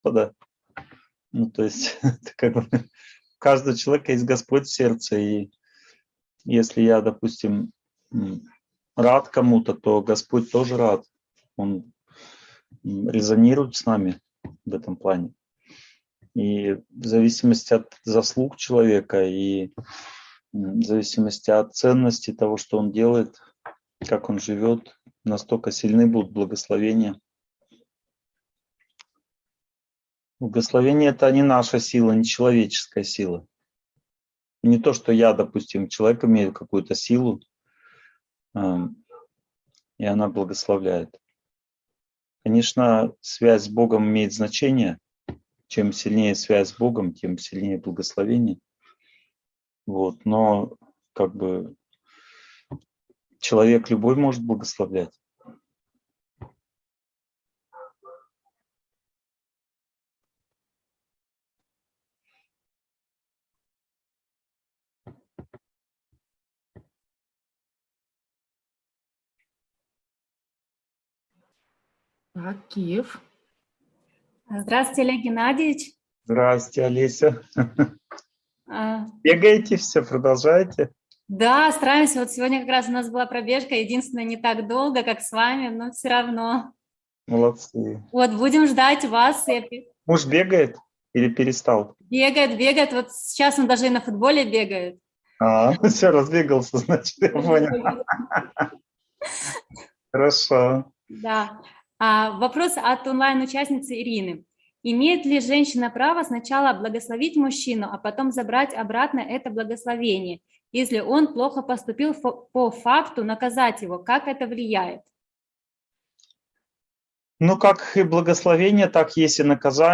Благословения. Их сила и важность (онлайн-семинар, 2021)